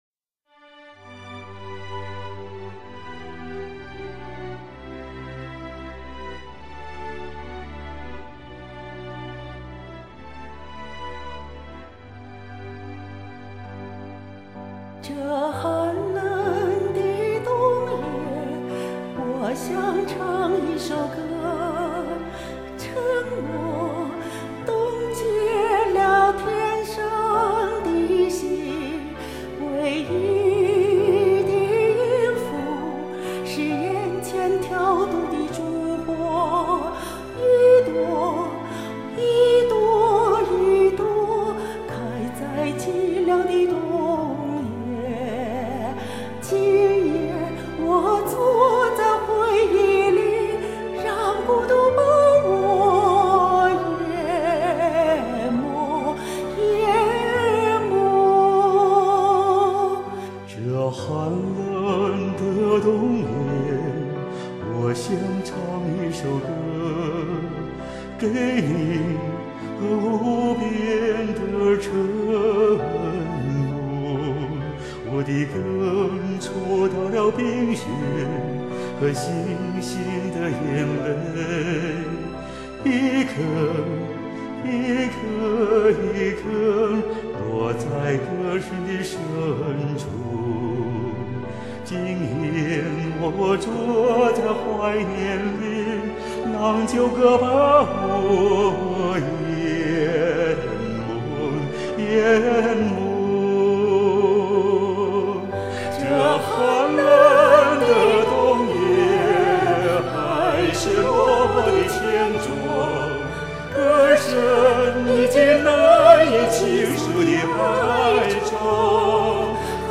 感觉男女合唱的话,作品更有内涵.